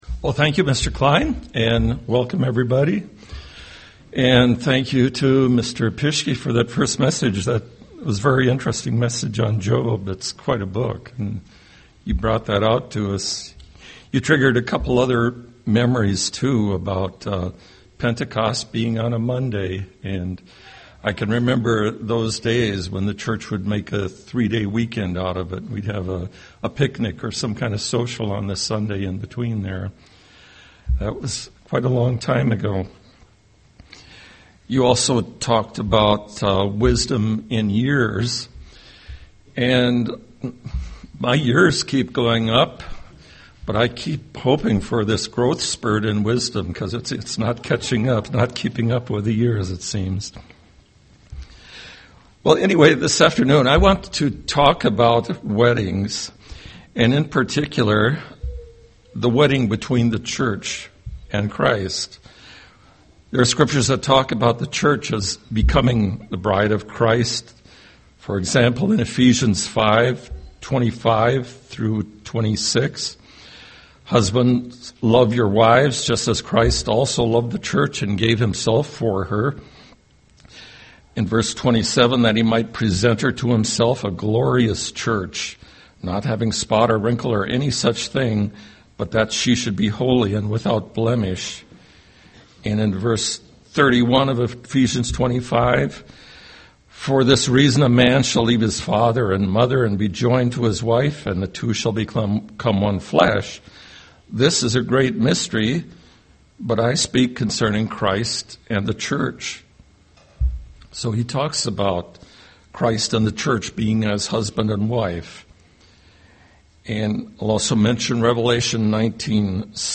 Given in Twin Cities, MN
UCG Sermon wedding wedding supper Studying the bible?